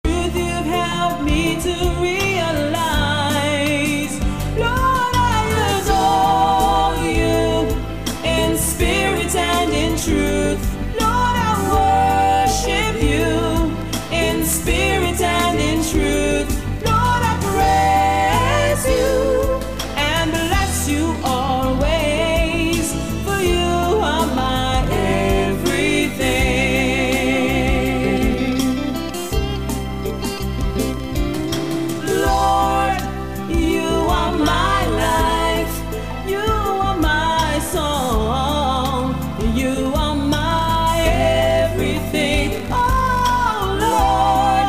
Nieznana piosenka gospel z radia karaibskiego
Potrzebuję pomocy w zidentyfikowaniu tej piosenki, którą nagrałem na 97.7 FM gdzieś na Karaibach.